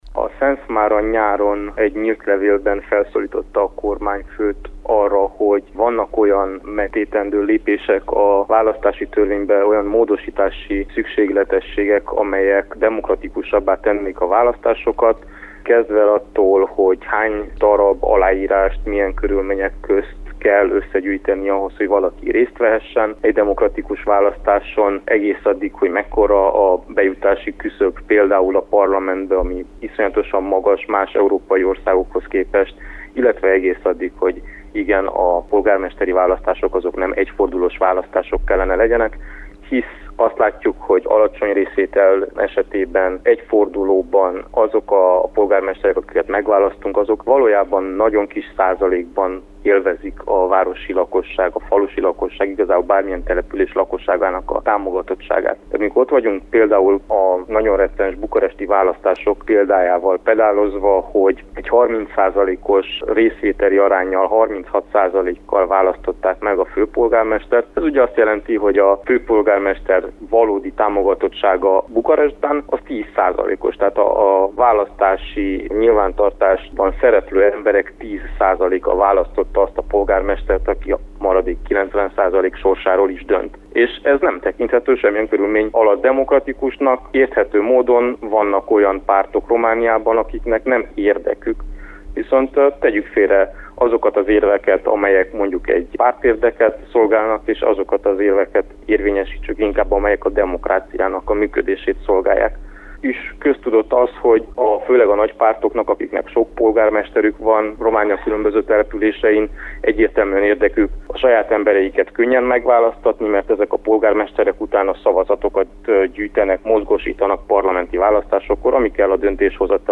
Politikusokat és politológust is megkérdeztünk a témában.